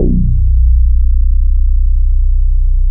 CHEESE BASS.wav